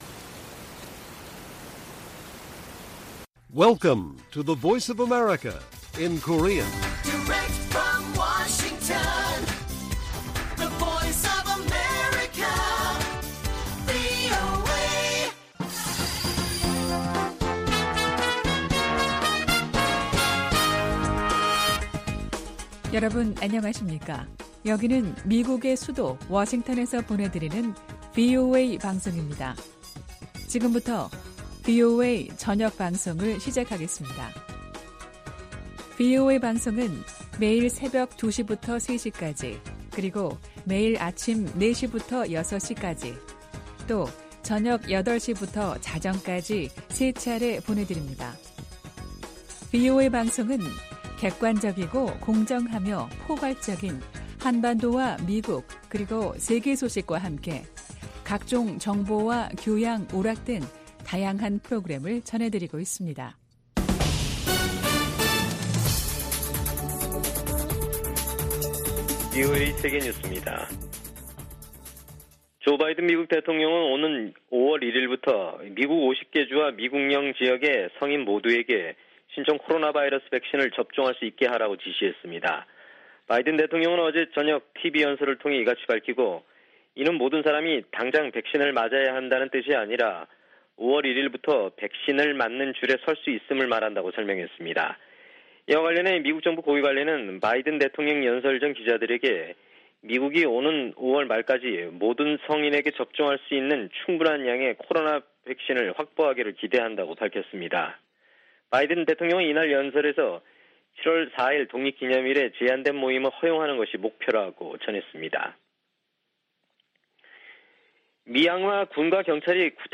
VOA 한국어 간판 뉴스 프로그램 '뉴스 투데이', 1부 방송입니다.